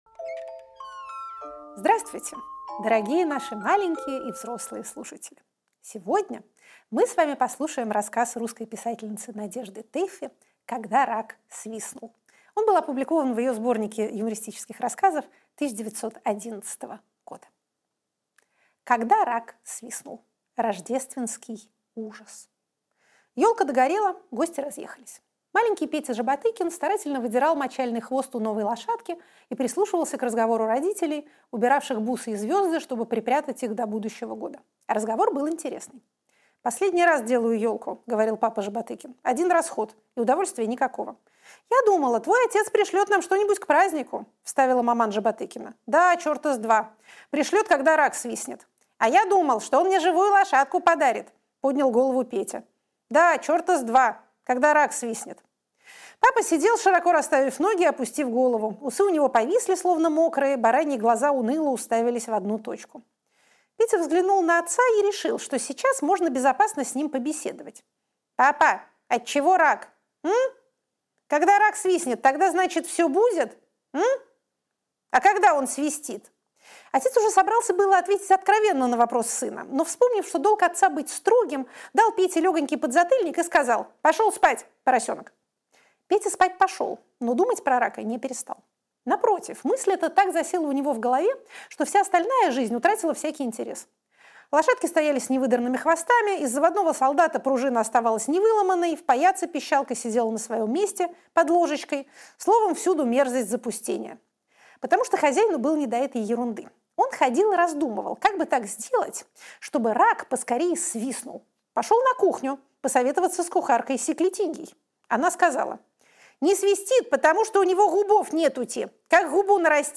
Фрагмент эфира от 05.01.26
Екатерина Шульман читает Тэффи. Юмористический рассказ «Когда рак свистнул»